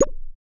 Cube Hit UI Game Sound 1.wav